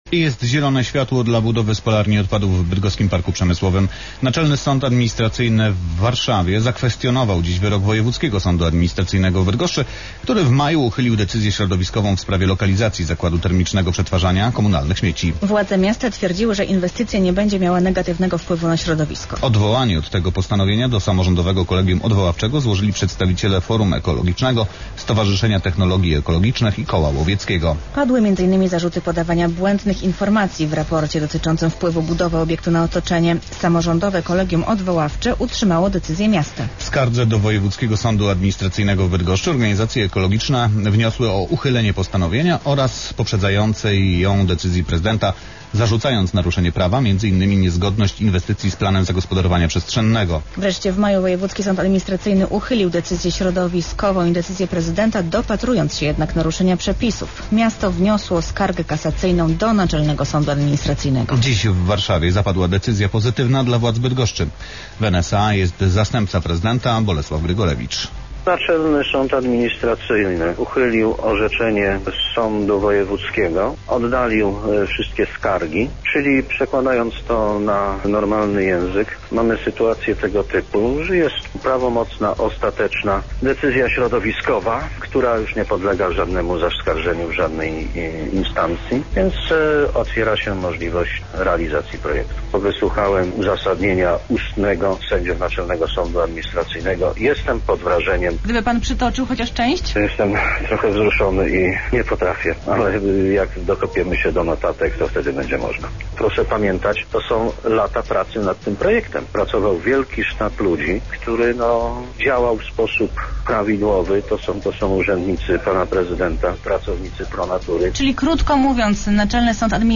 wiadomosci-polskiego-radia-pik.wav